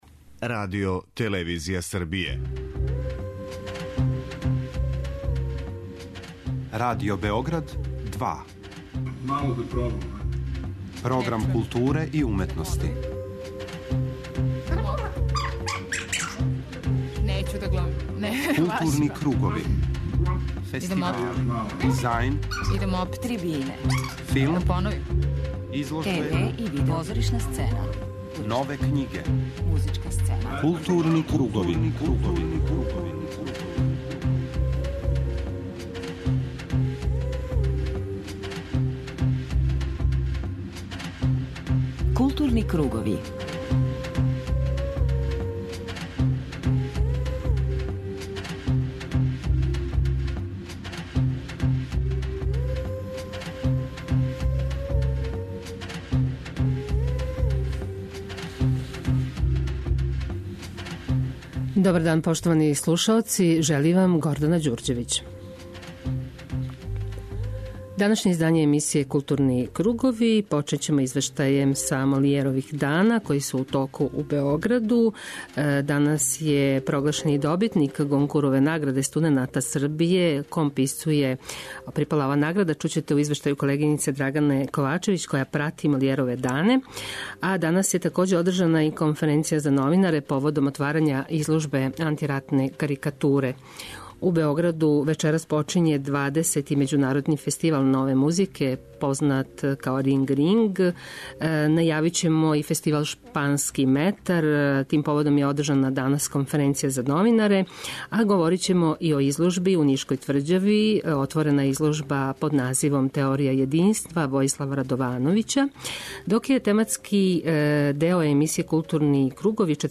преузми : 52.60 MB Културни кругови Autor: Група аутора Централна културно-уметничка емисија Радио Београда 2.